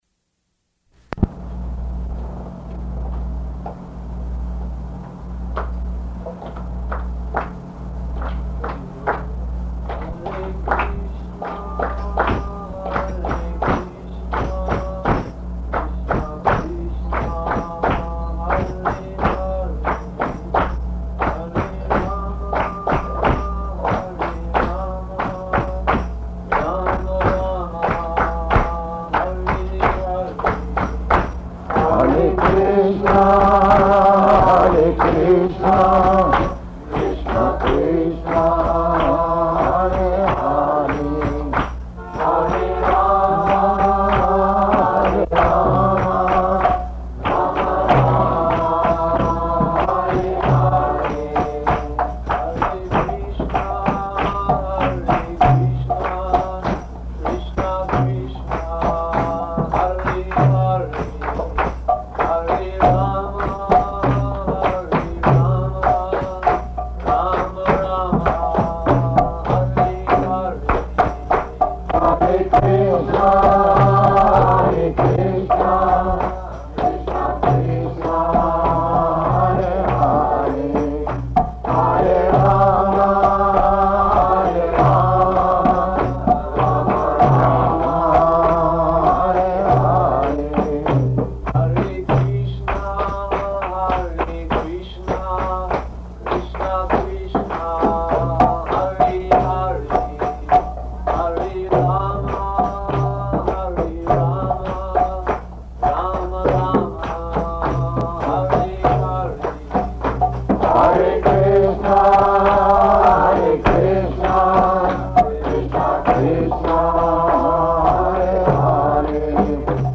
Kīrtana and Room Conversation